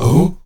AHOO F#3A.wav